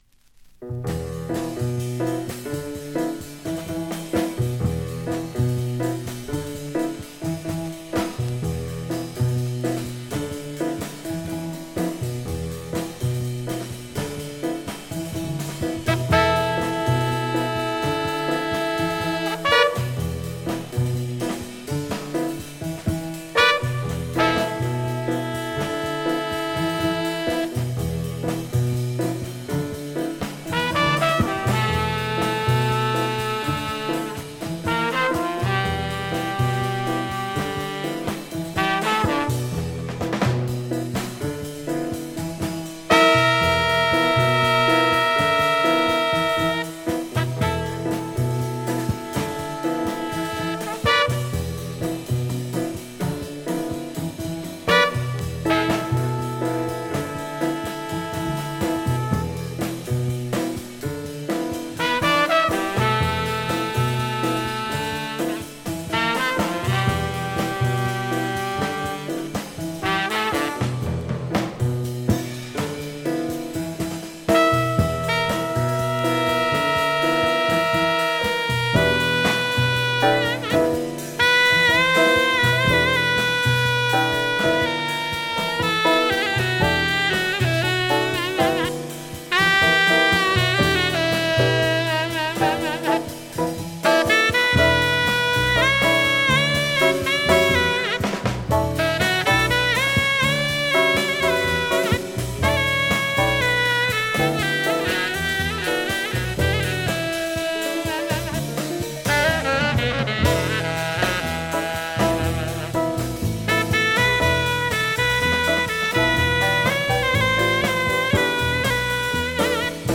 ディープでエキゾチックな「